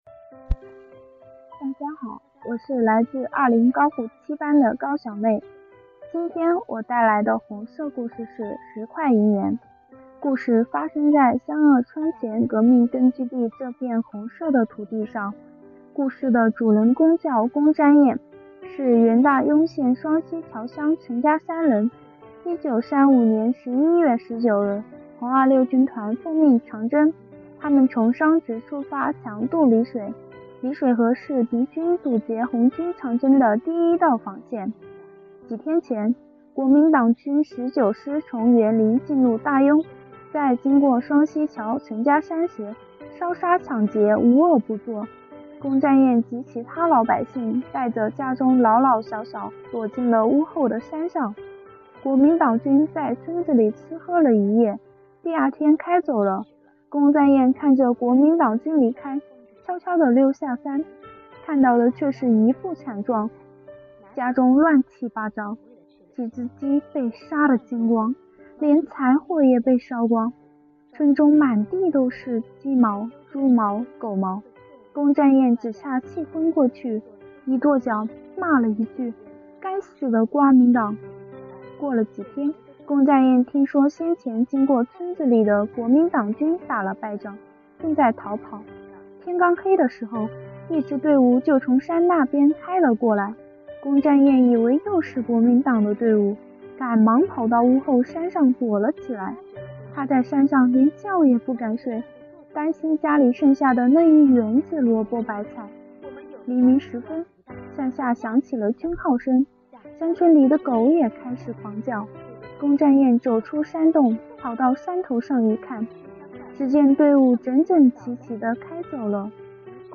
朗读者